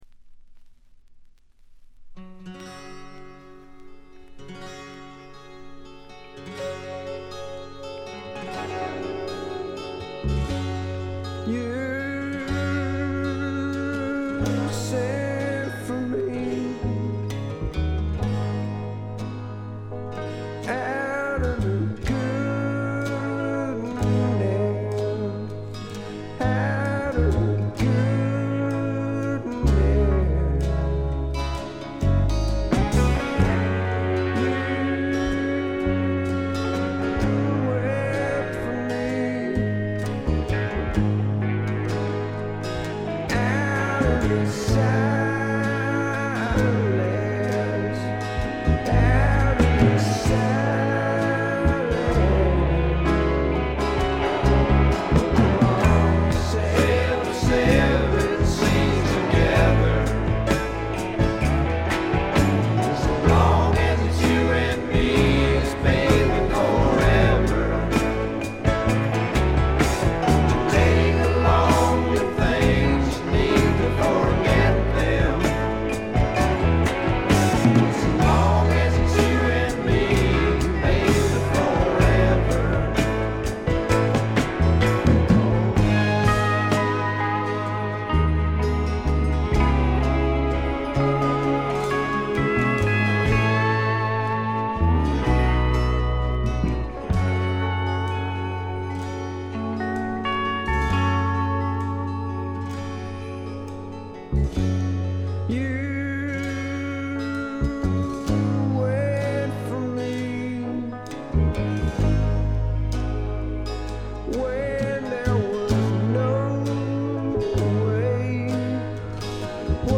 軽微なバックグラウンドノイズにチリプチ少々。
試聴曲は現品からの取り込み音源です。